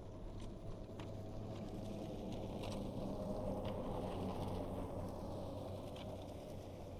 Zero Emission Snowmobile Description Form (PDF)
Zero Emission Subjective Noise Event Audio File (WAV)